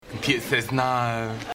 Play computer says no, Download and Share now on SoundBoardGuy!